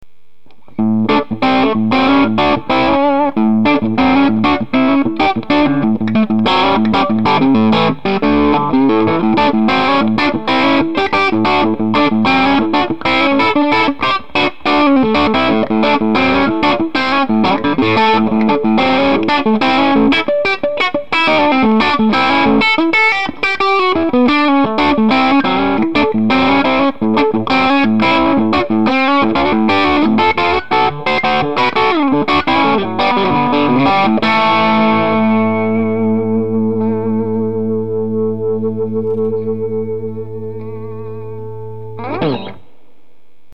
Pour les samples c'est strat srv direct ds l'amp repris par un sm57 branché dans ma carte son,j'ai fait a chaque fois deux samples pour montrer l'influence des medium sur le son,un creusé et un avec les medium bien poussés,(sans toucher aux autres potars que ce soit le volume le master ou les basses et les aigus):
Le boost est tres efficace et permet d'avoir un gros crunch dynamique(pas metal).
Boost medium.mp3